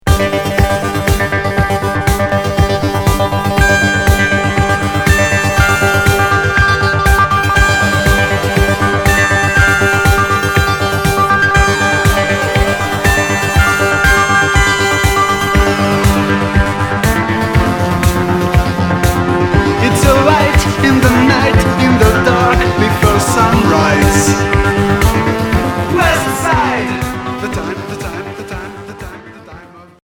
Synthétique